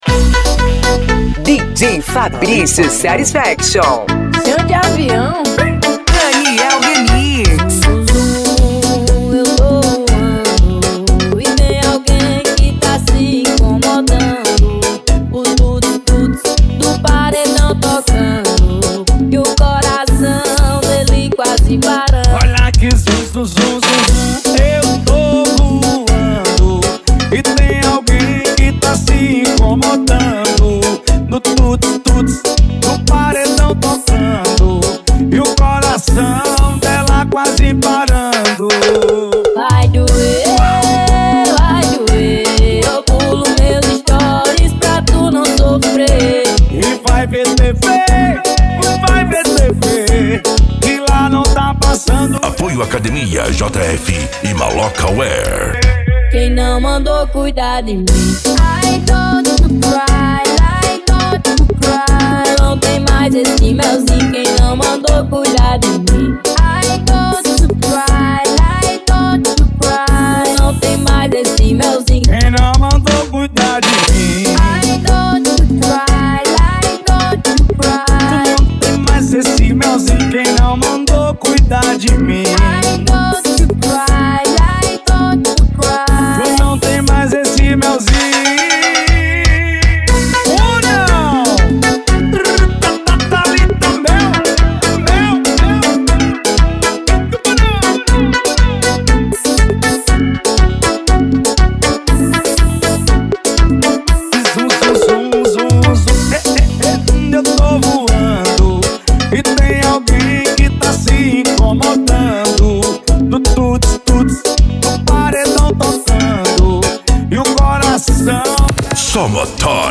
SERTANEJO